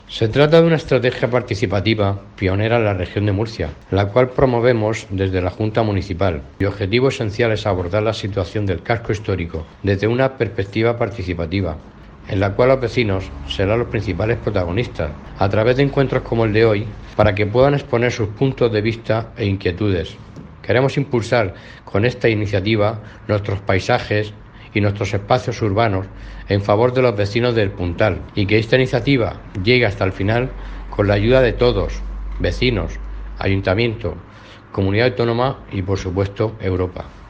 audio_pedáneoElPuntal_SalvadorRos.mp3